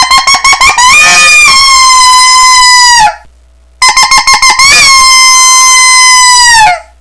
Listen to 7 seconds of coyote howls
Also makes good Coyote Howls.
qbcoyote7.wav